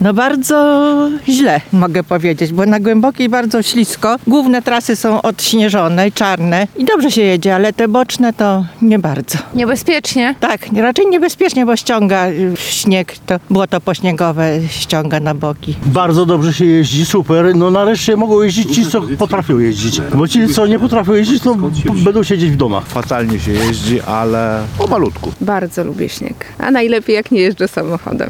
– Sytuacja na drogach nie jest najlepsza – przyznają kierowcy.